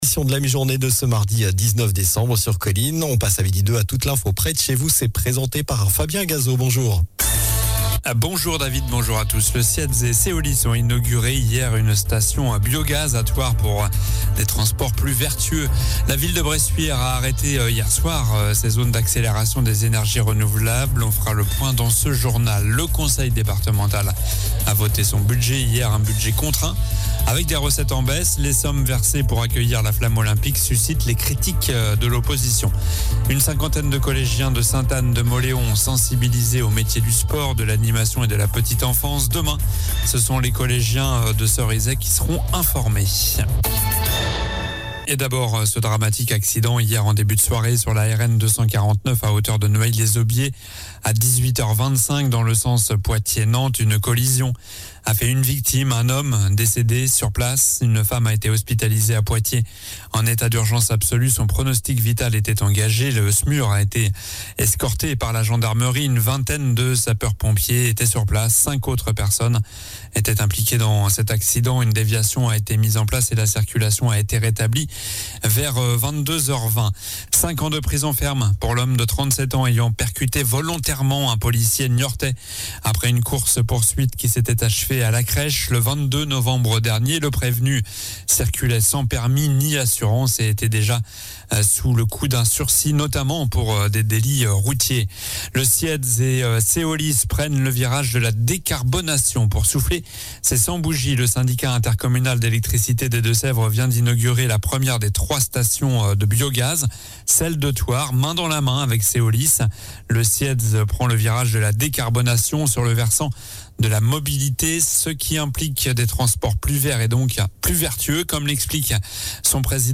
Journal du mardi 19 Décembre (MIDI)